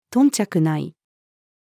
頓着ない-female.mp3